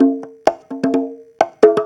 Conga Loop 128 BPM (15).wav